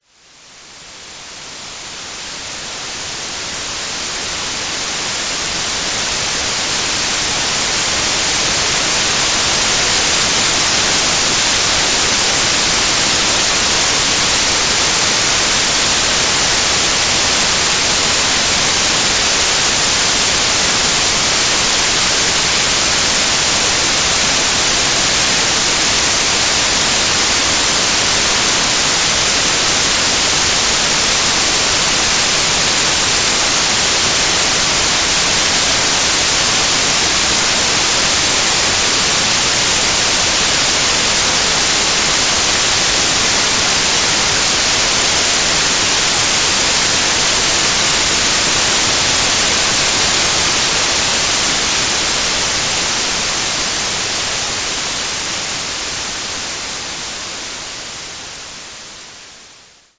Im Gegensatz zu Hintergrundgeräuschen ändert sich das Rauschen nicht. Der Ton bleibt immer gleich.
WHITE NOISE
White Noise reinhören
white-noise-preview.mp3